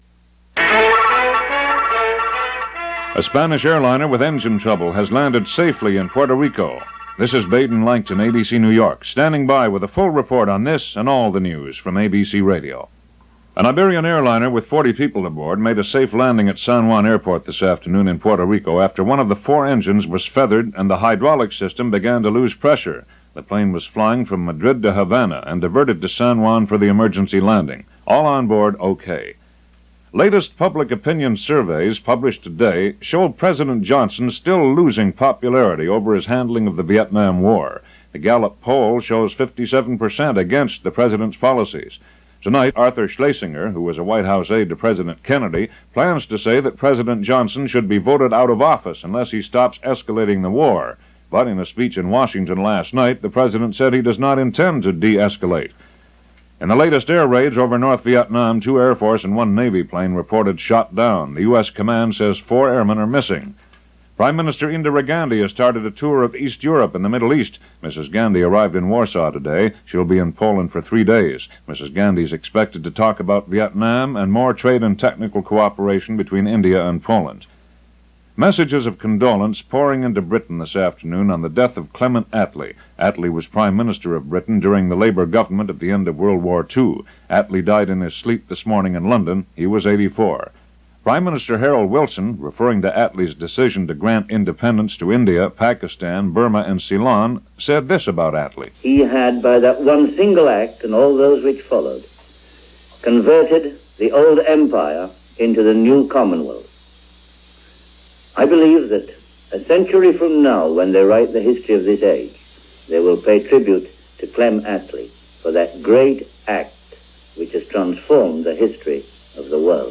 And that’s a small slice of what happened, this October 8, 1967 as reported by ABC Radio’s News On The Hour.